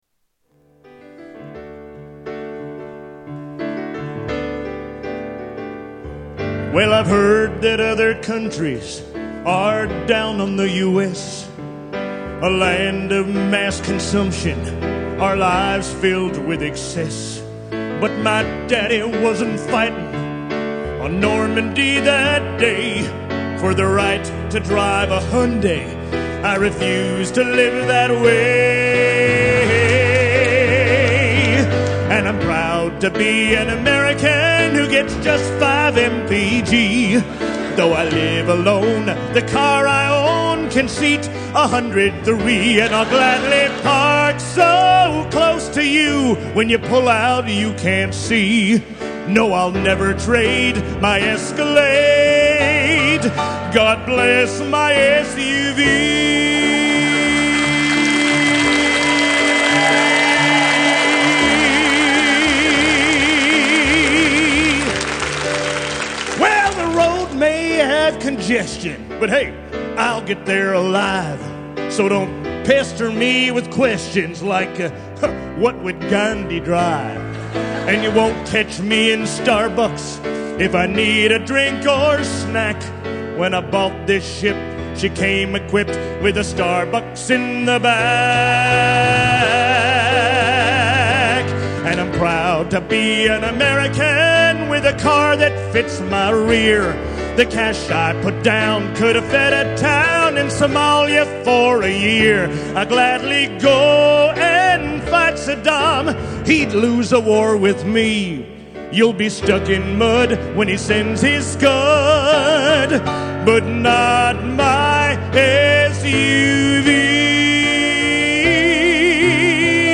Tags: Media More Parodies Clips Parodies Songs Comedy Spoofs